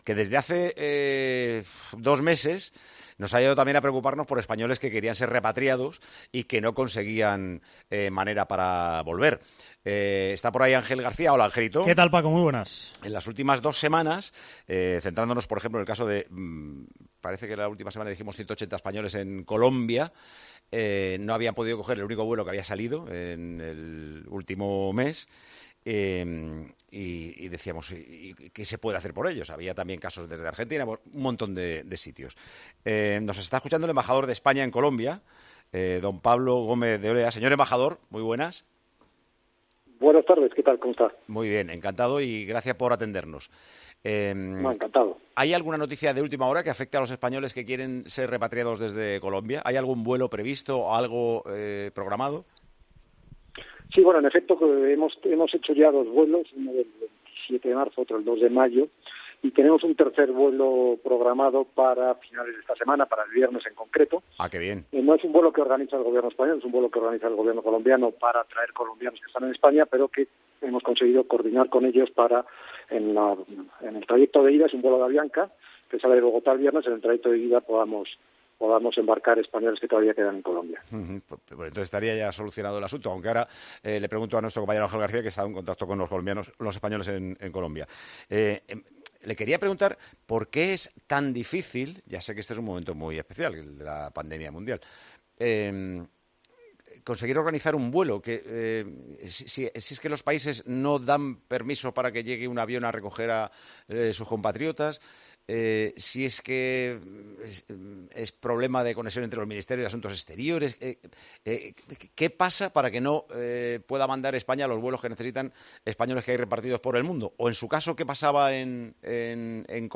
El embajador de España en Colombia ha explicado que hay muchas dificultades para poder repatriar a los más de 300 españoles que están atrapados en el país sudamericano.